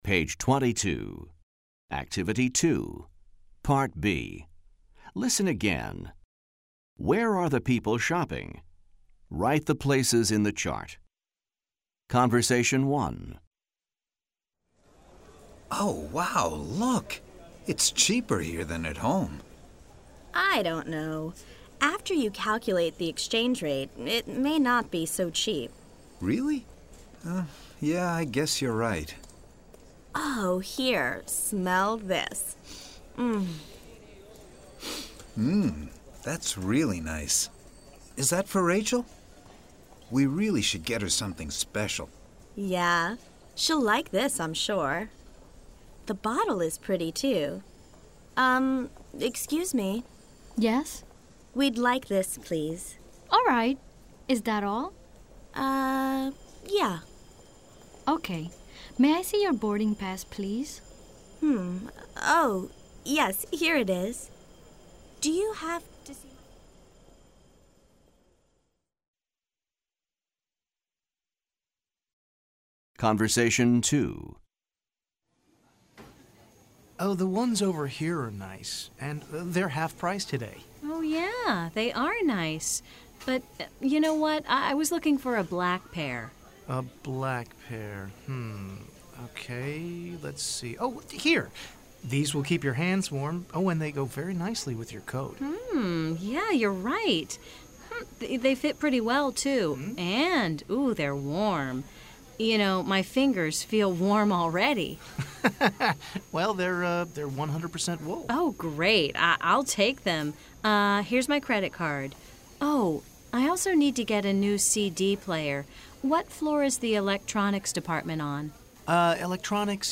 American English
New recordings offer authentic listening experiences in a variety of genres, including conversations, interviews, and radio and TV shows.